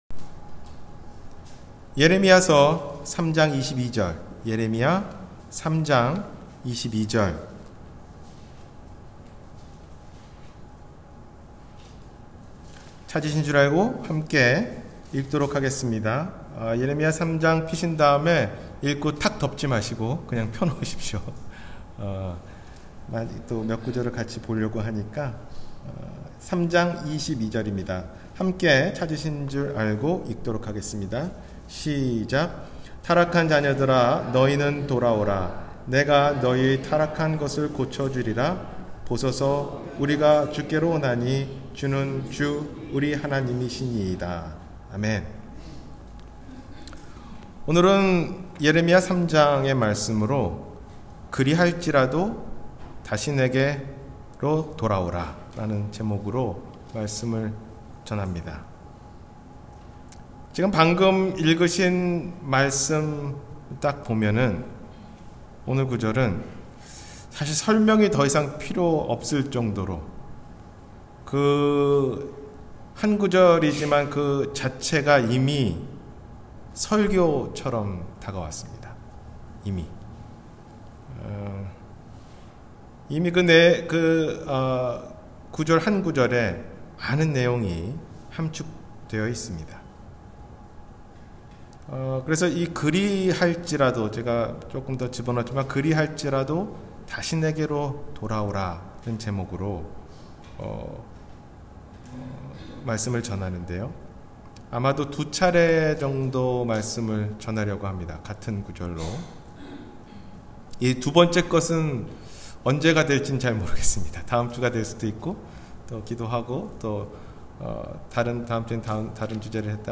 그러할지라도 다시 내게 돌아오라 – 주일설교